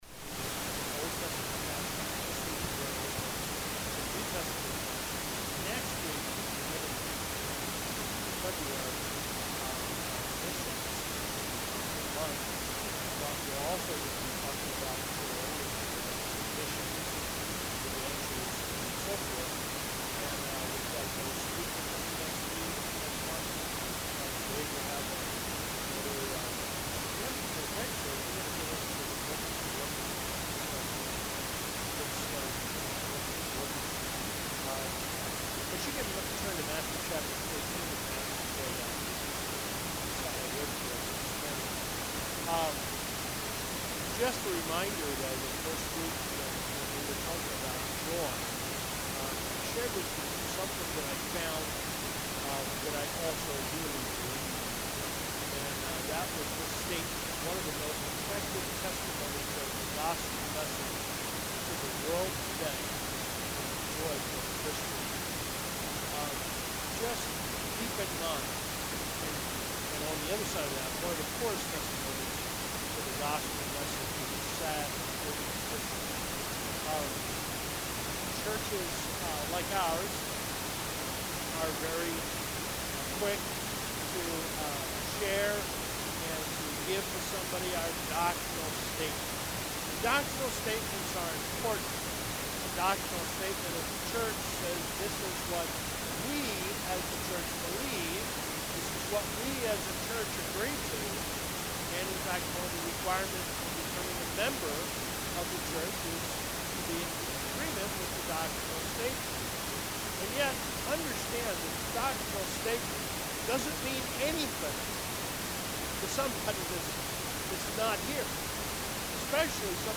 Bible Baptist Church Sermon Archive for January, 2026